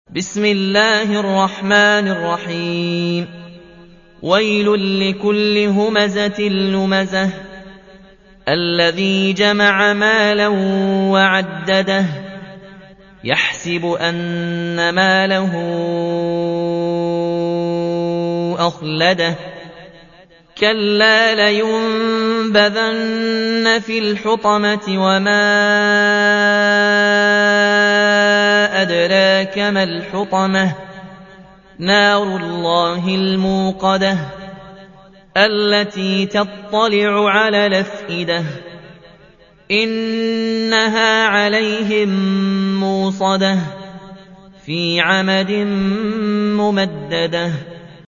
104. سورة الهمزة / القارئ